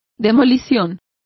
Complete with pronunciation of the translation of demolition.